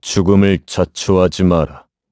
Clause_ice-Vox_Skill5_kr.wav